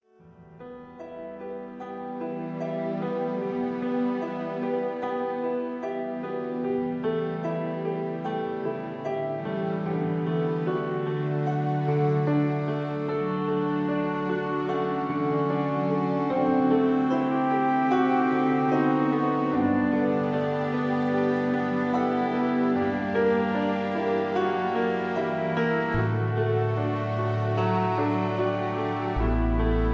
(Key-D, Tono de D) Karaoke MP3 Backing Tracks